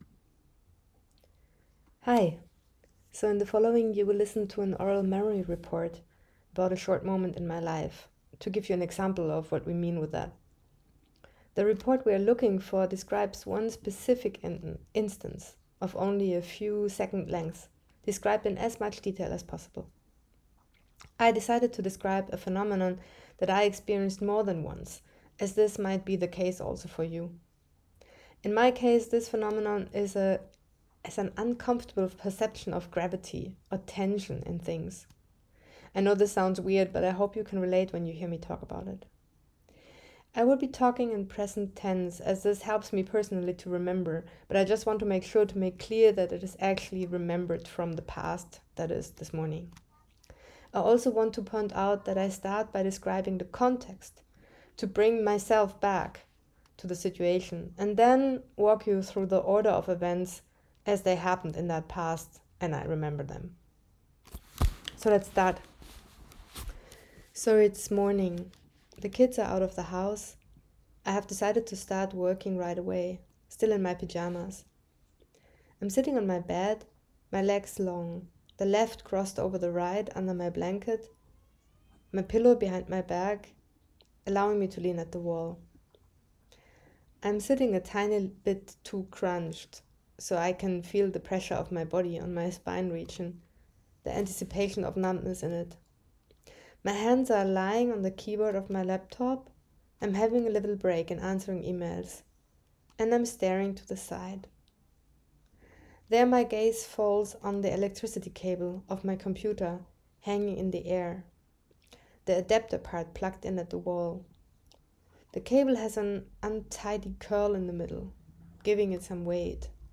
MP-interview_example.m4a